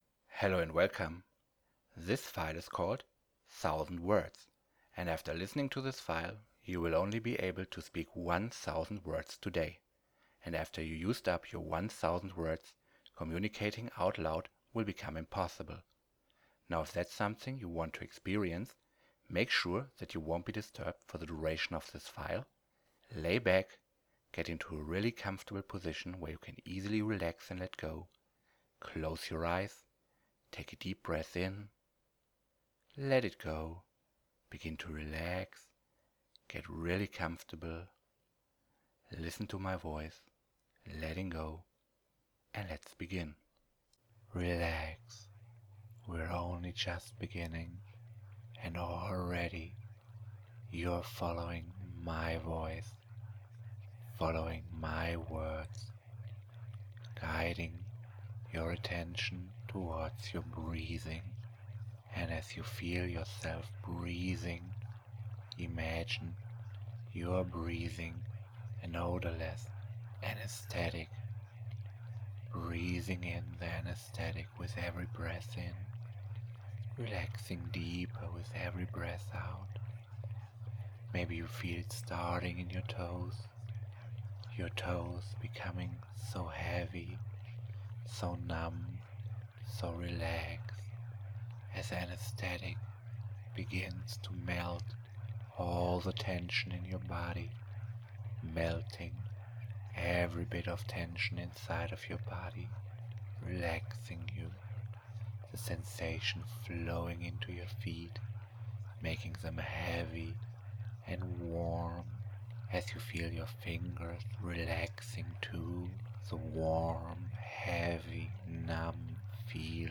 Listen to this Hypnosis for free now! 1000 Words (Mind Melt Version) You like what you hear?